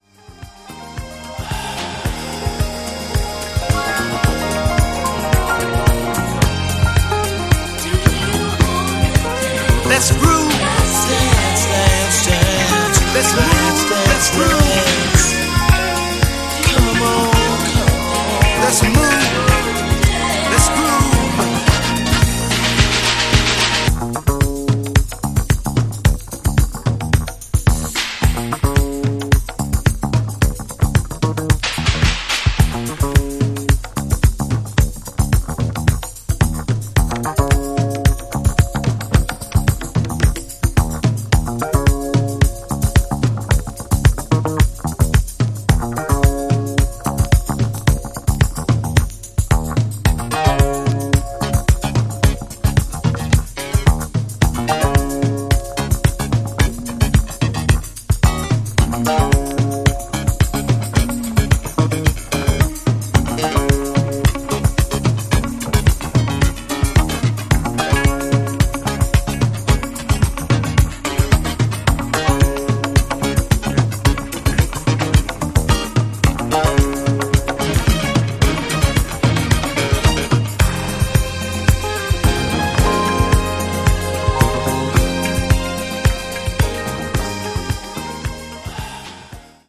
shifts gears into a mellower, psychedelic jazz disco zone